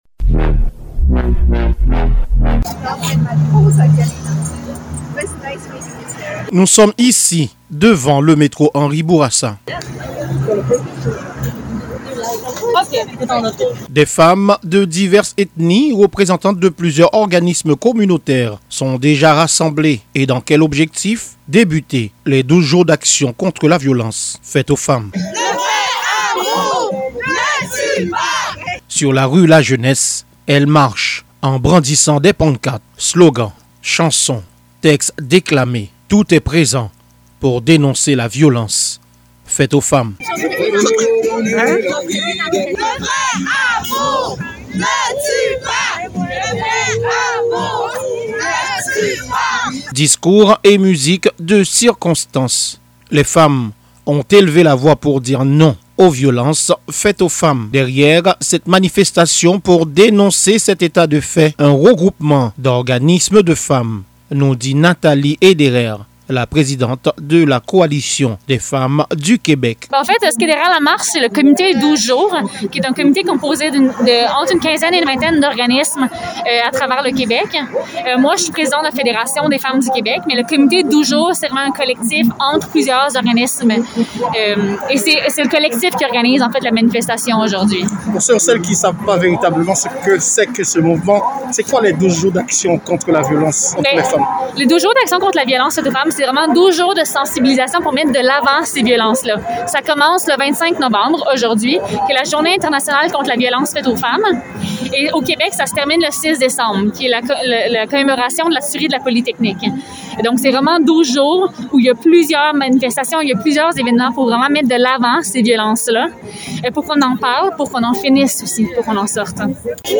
REPORTAGE-lundi-29-novembre-VIOLENEC-CONTRE-LES-FEMMES-12-JOURS-DACTION.mp3